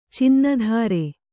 ♪ cinna dhāre